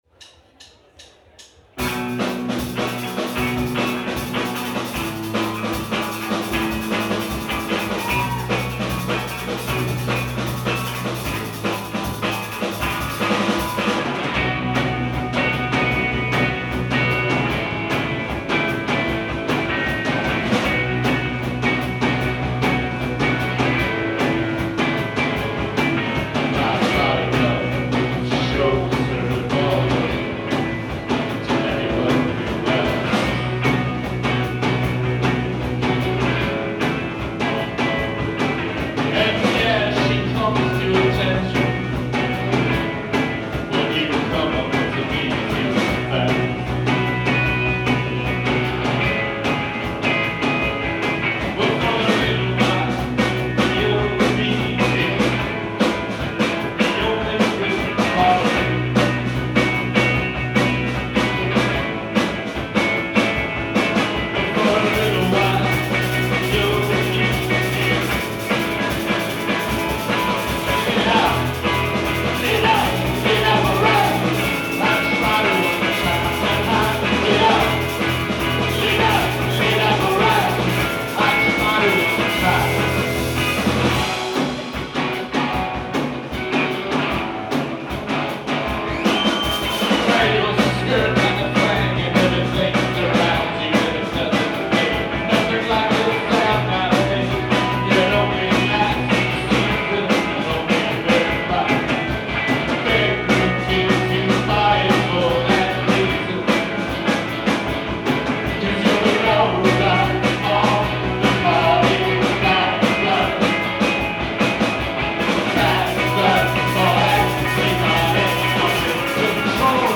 live at TT the Bears